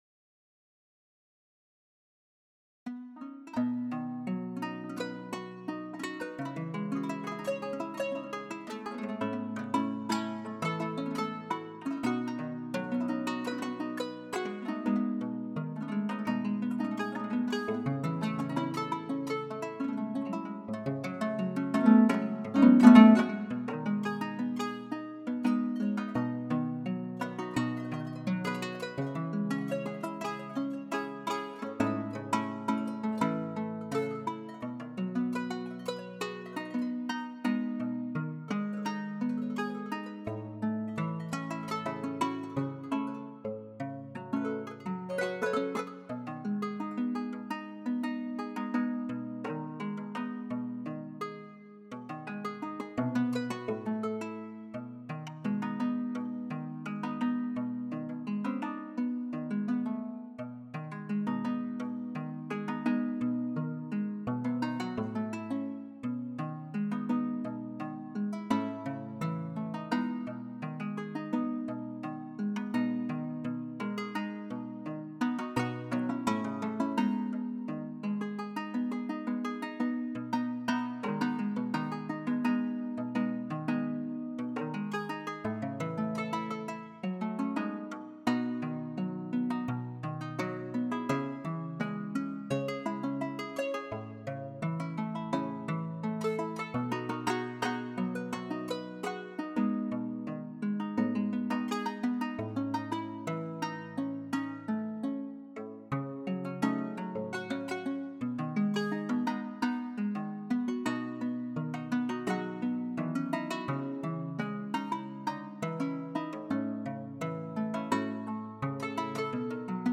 Gespielt wird sie mit einem Plektrum, oder mit Fingerhüten aus Metall.
Einige Klangbeispiele traditioneller Musik.
Der Klang ist durch die Art der Deckenbespannung typisch.
qanun1.mp3